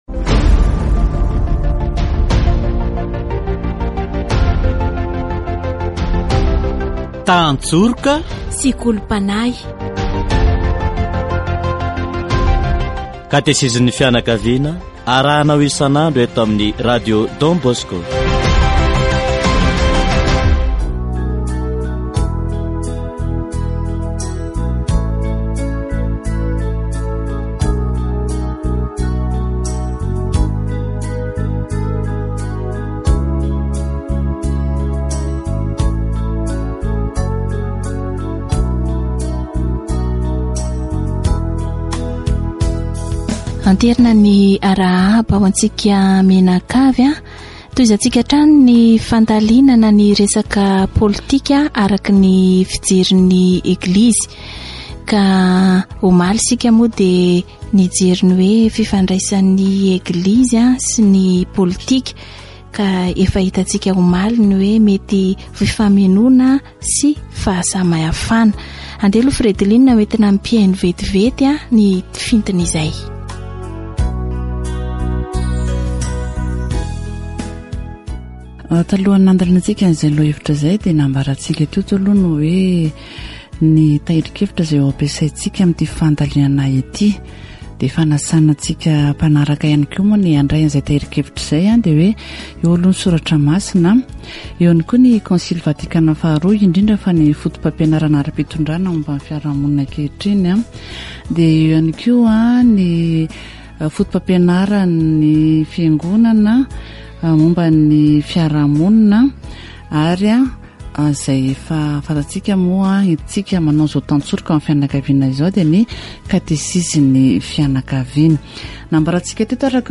Ce qui devrait gouverner les politiciens, c'est l'autorité de Dieu, qui utilise l'autorité selon la justice et la droiture, selon la conscience. Catéchèse sur la politique selon l'avis de l'Église